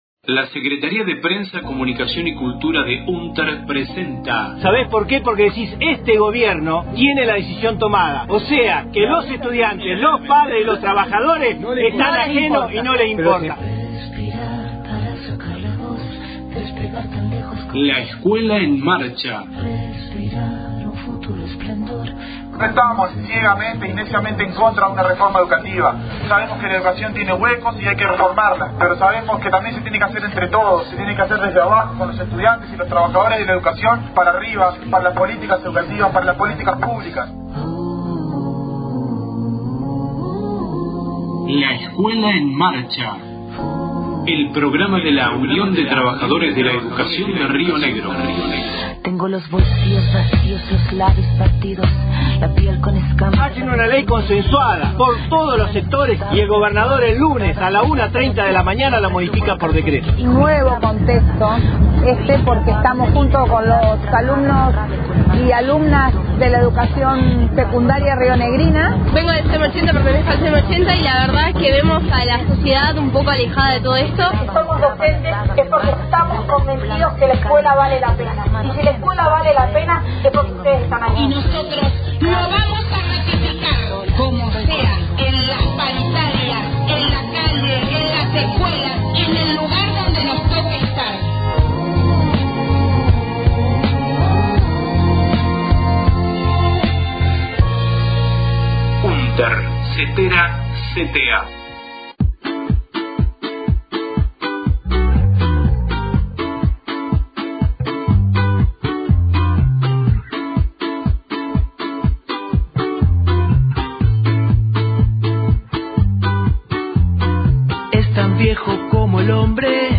Audio movilización Roca – Fiske Menuco 26/07/18, en el marco de la protesta nacional contra el decreto de Macri, que avala la intervención de las Fuerzas Armadas en las calles del país.